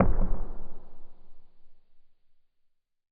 explosion_far_distant_06.wav